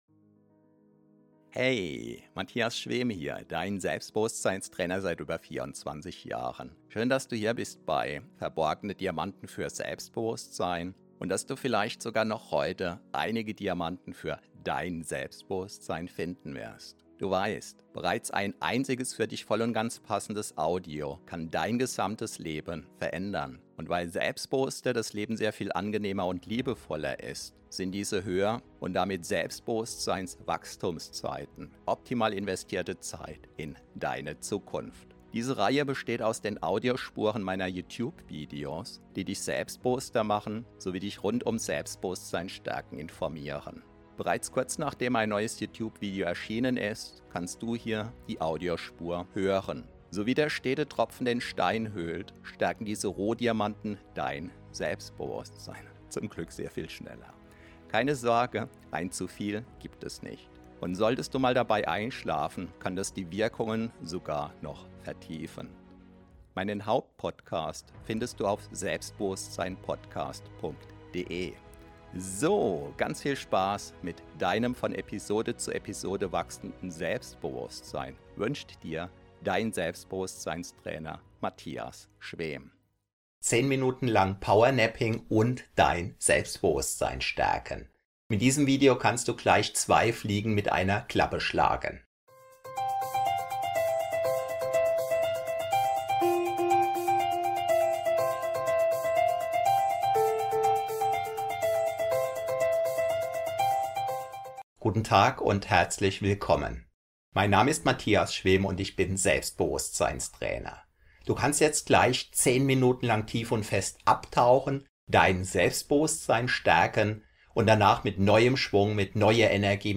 Powernap 10 min zum Selbstbewusstsein stärken Meditation Mittagsschlaf PowerSchlaf Tiefenentspannung ~ Verborgene Diamanten Podcast [Alles mit Selbstbewusstsein] Podcast